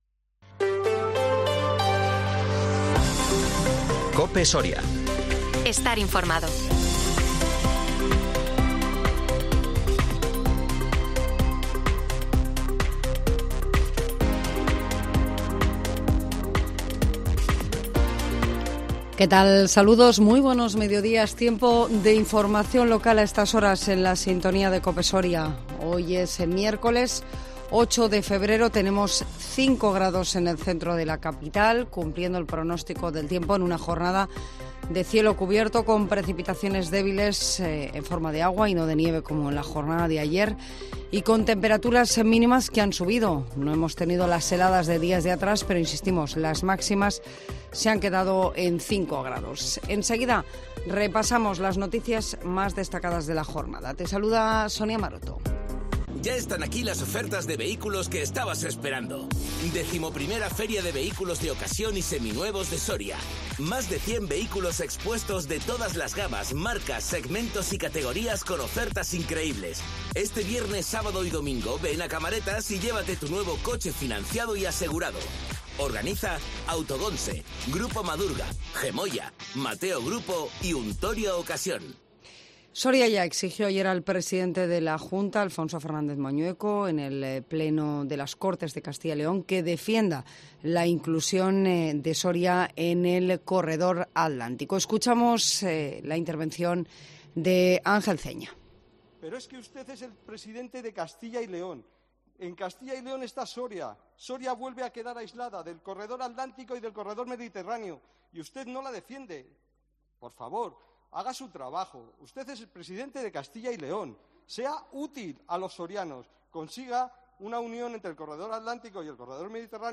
INFORMATIVO MEDIODÍA COPE SORIA 8 FEBRERO 2023